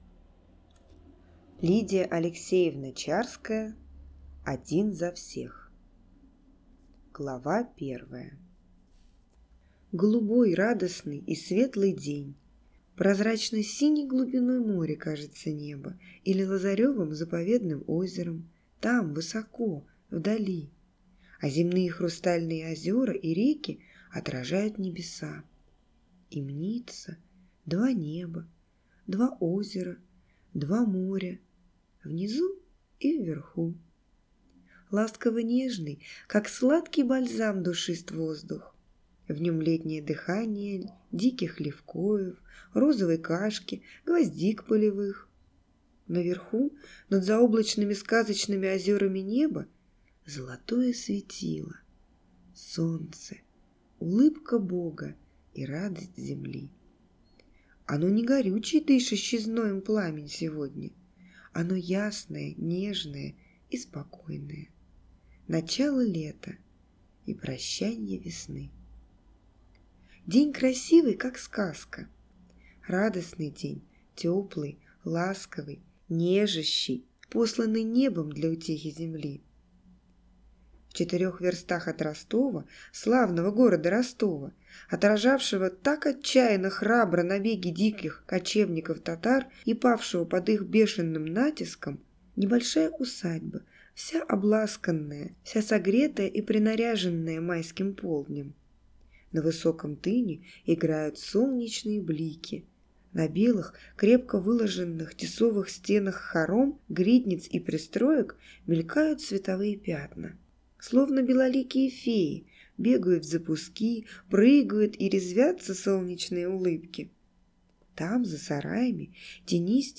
Аудиокнига Один за всех | Библиотека аудиокниг
Прослушать и бесплатно скачать фрагмент аудиокниги